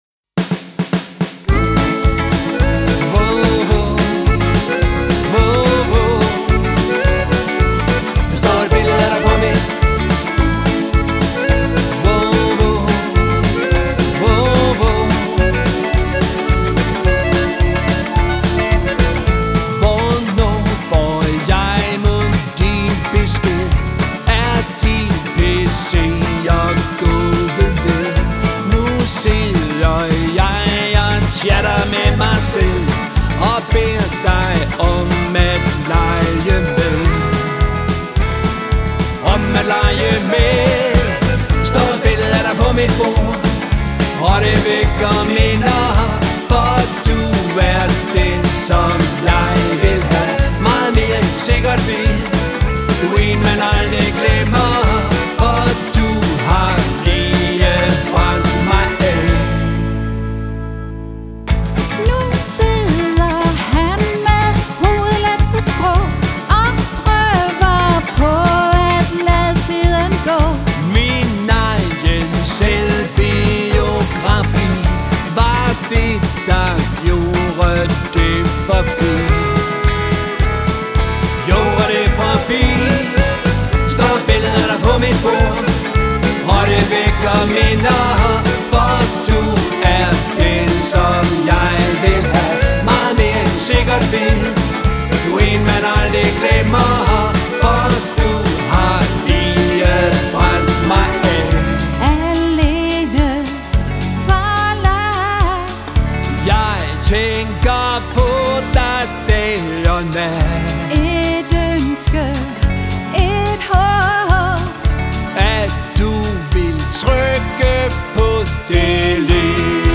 guitar og vokal
keyboards og vokal
• Coverband